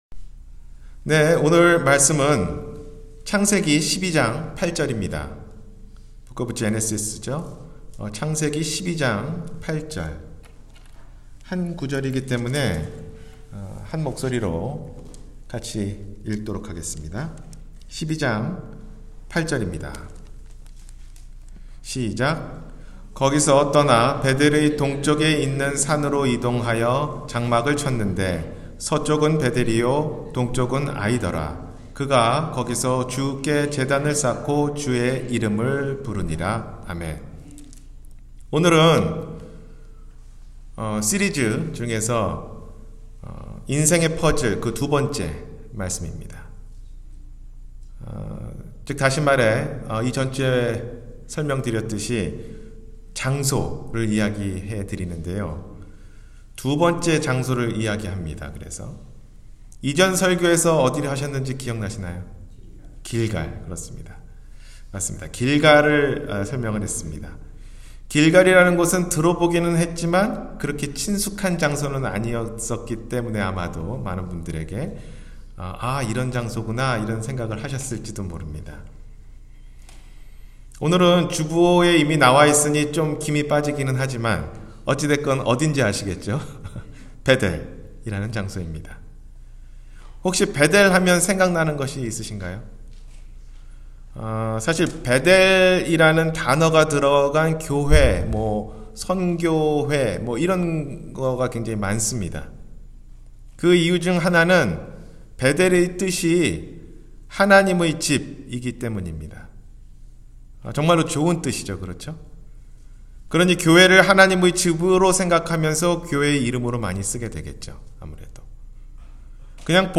인생의 퍼즐 2: 벧엘-주일설교